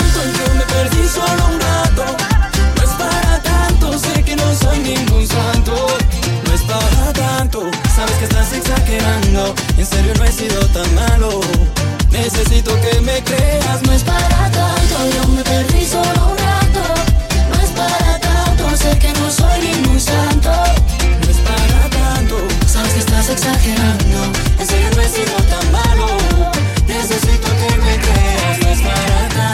Genere: pop latin, urban latin, reggaeton, remix